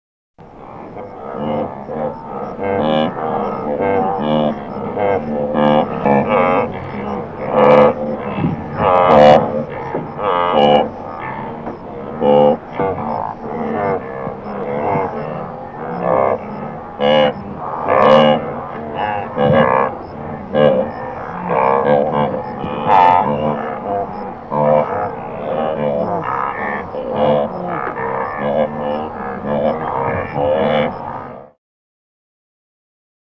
Il grogne, ron e et meugle
gnou.mp3